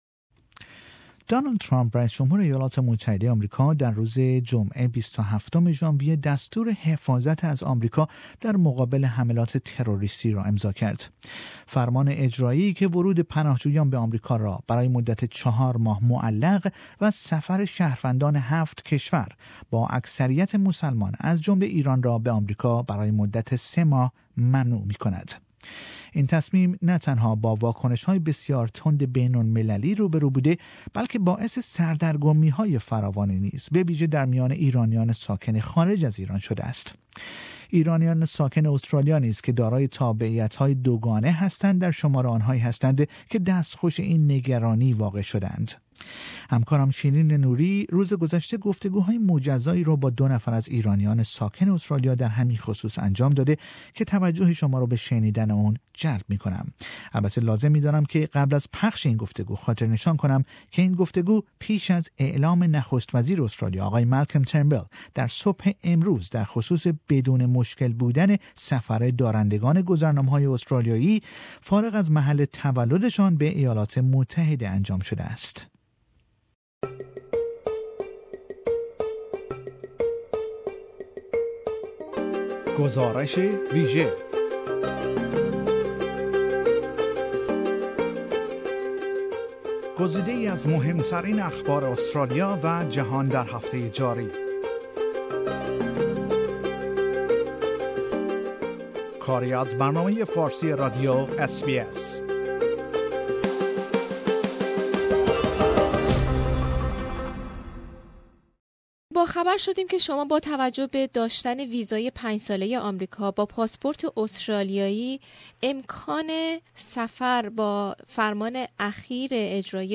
ایرانیان ساکن استرالیا نیز که دارای تابعیت دو گانه هستند در شمار آنهایی هستند که دستخوش این تغییرات قرار گرفته اند. در این گفتگو تعدادی از شهروندان ایرانی-استرالیایی ساکن استرالیا درباره تاثیرات این تحریم جدید سخن می گویند.